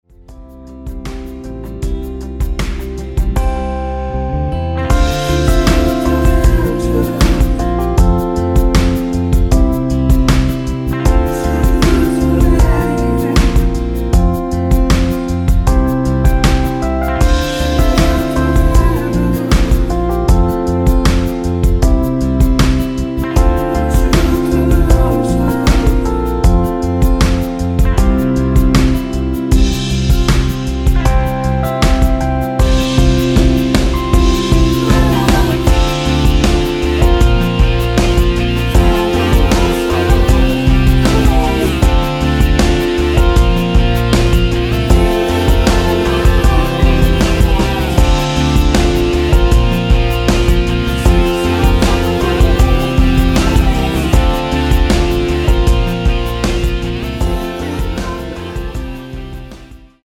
Dm
앞부분30초, 뒷부분30초씩 편집해서 올려 드리고 있습니다.
곡명 옆 (-1)은 반음 내림, (+1)은 반음 올림 입니다.